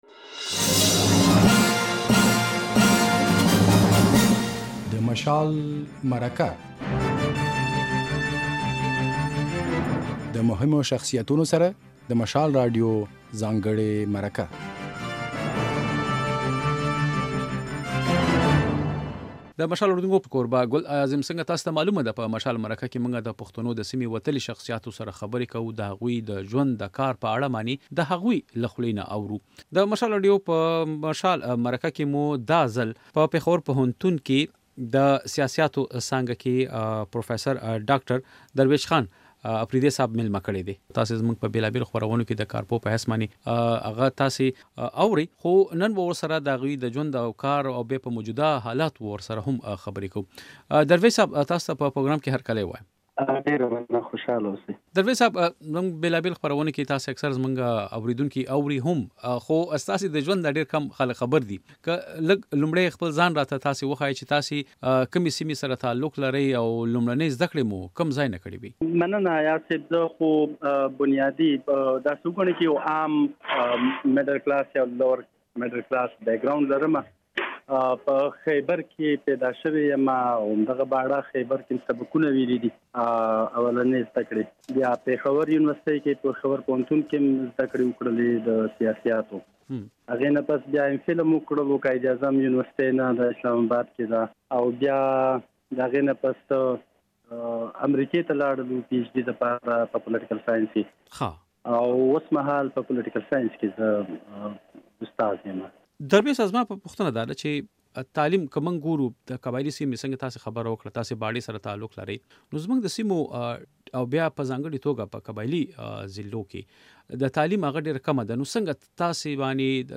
نوموړي زياته کړه چې په موجوده حالاتو کې د جمهوريت په اړه يوې بلې لوظنامې ته اړتيا ده. بشپړه مرکه واورئ.